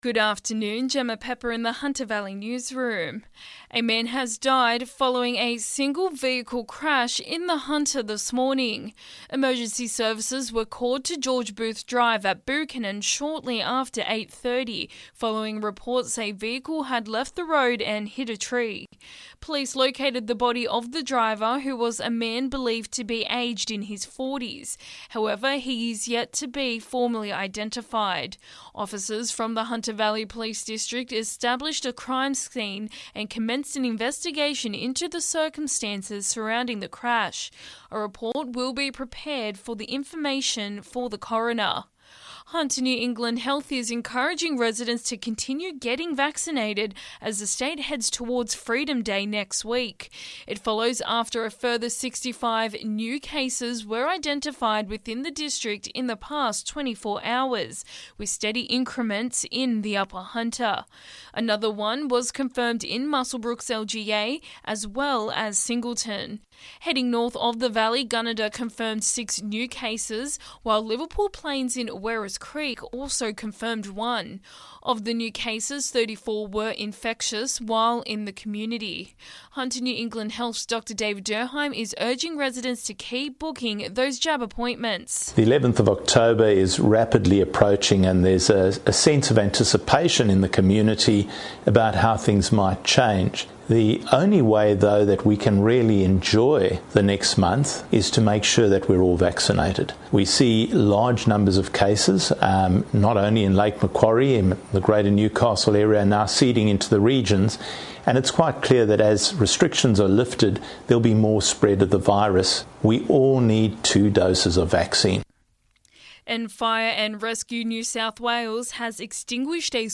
Listen: Hunter Local News Headlines 05/10/2021